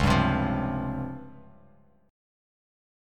Listen to DbM7b5 strummed